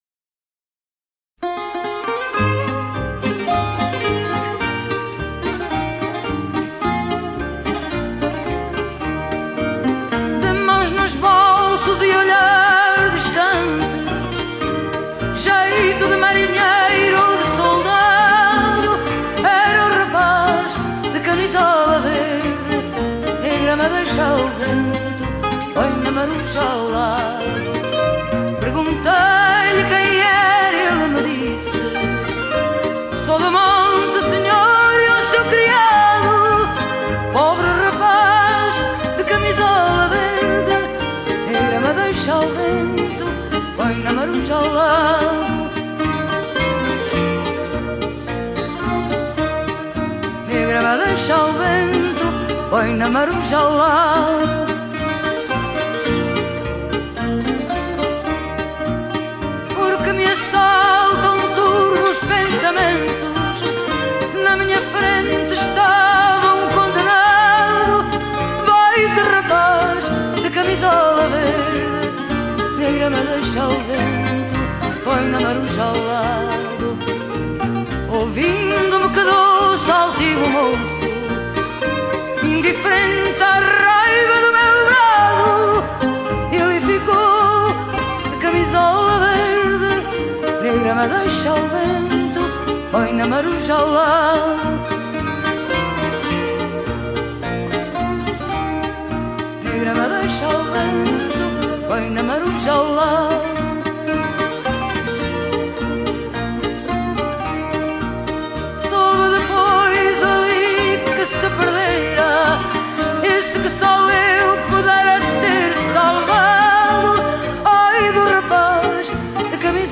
chant
guitare portugaise
guitare basse acoustique.
from → Adorable, Fado
a un côté tragique et martial